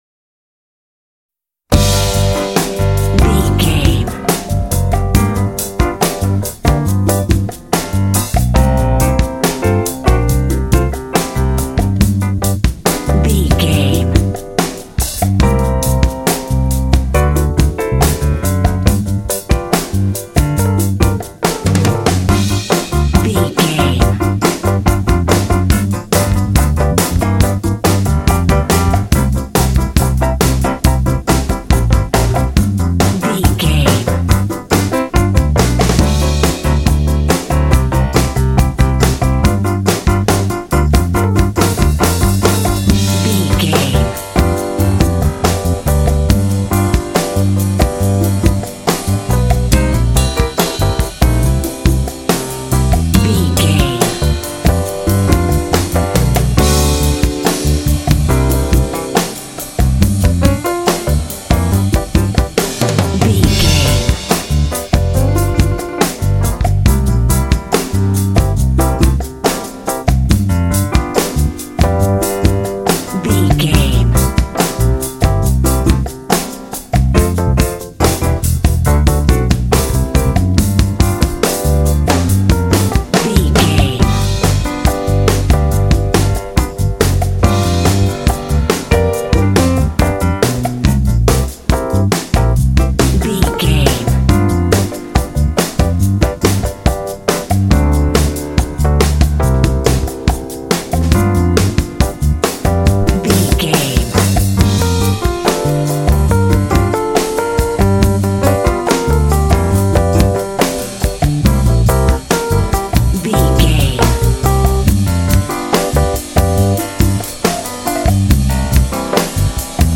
Aeolian/Minor
funky
groovy
bright
drums
bass guitar
piano
electric piano
jazz